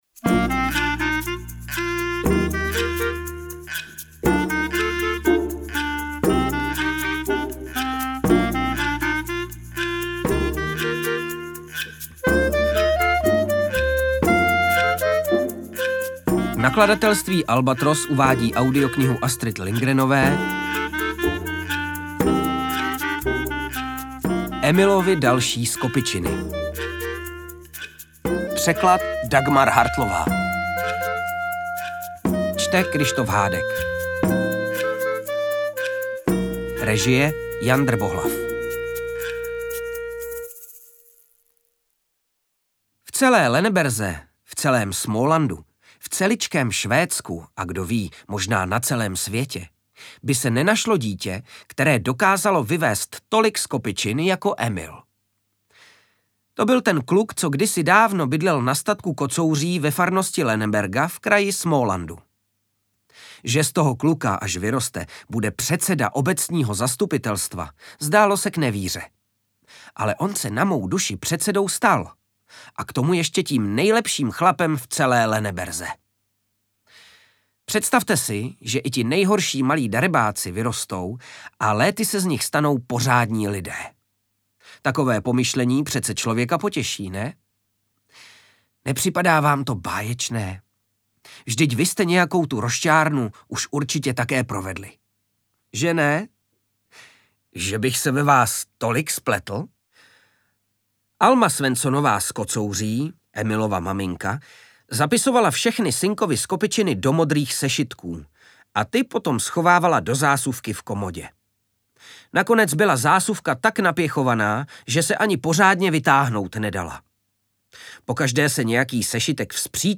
Interpret:  Kryštof Hádek
AudioKniha ke stažení, 12 x mp3, délka 3 hod. 9 min., velikost 258,4 MB, česky